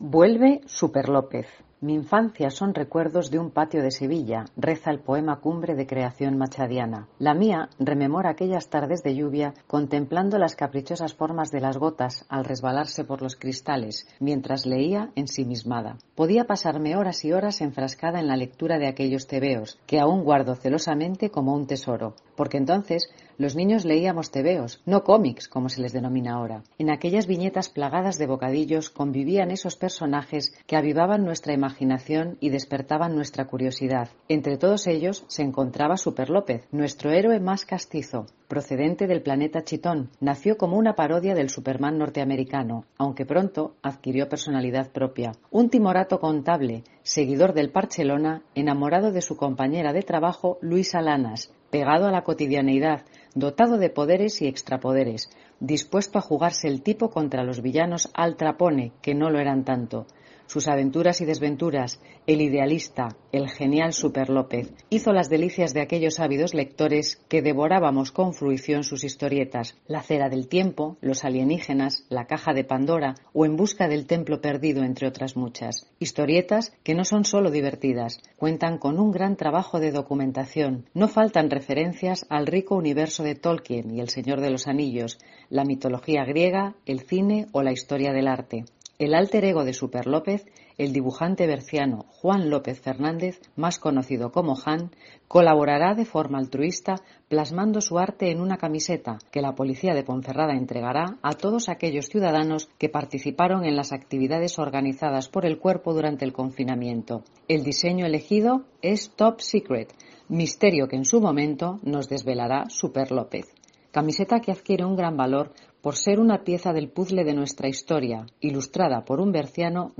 OPINIÓN-SOCIEDAD